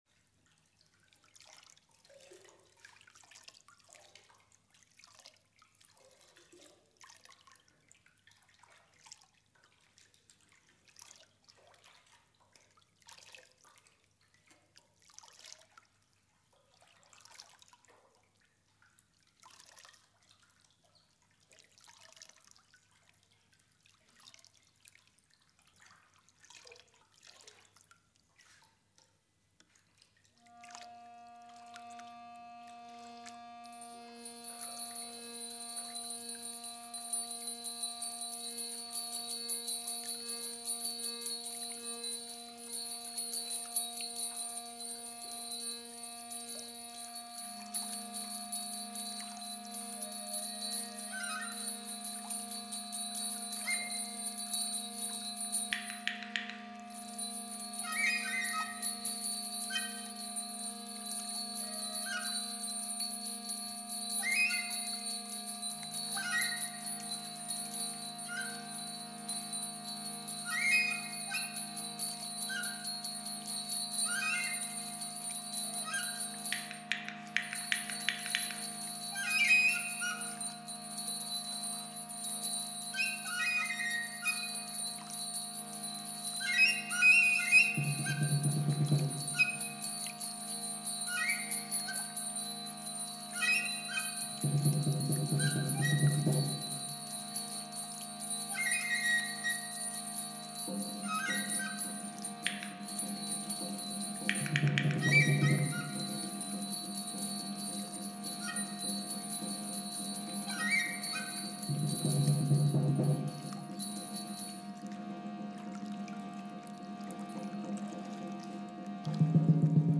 percussions
Enregistrement du concert donné à la Maison de Quartier de la Jonction